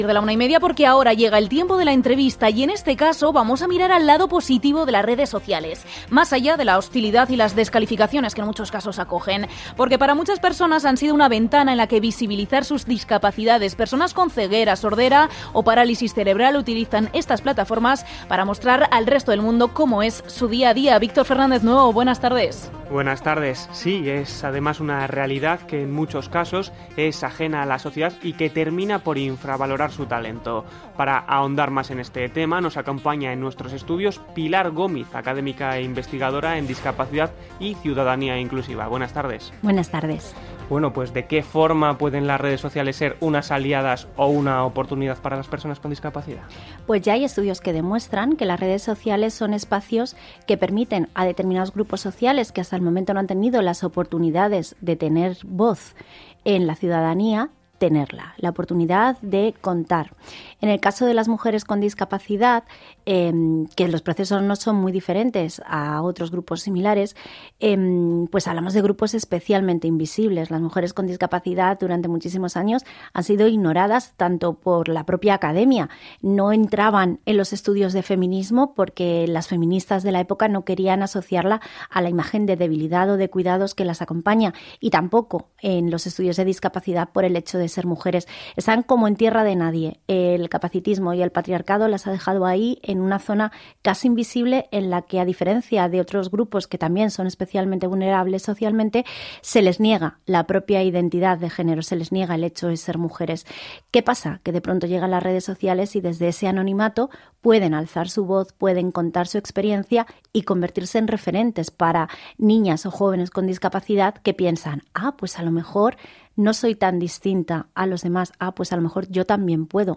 Aquí os dejamos ambas entrevistas: